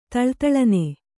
♪ taḷtaḷane